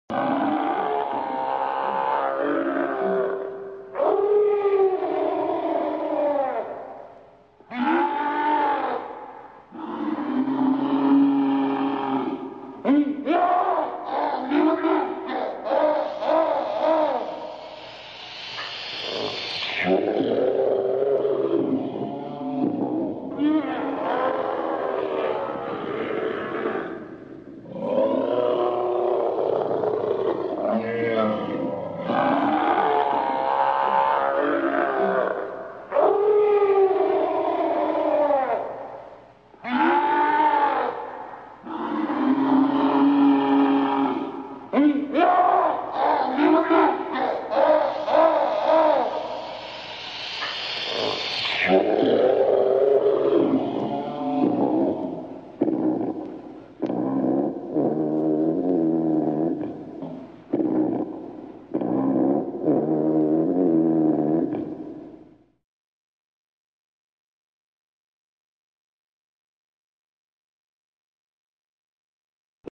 Так кричать может монстр в заточении, звучит его невнятная речь и стоны
Krik_monstra.mp3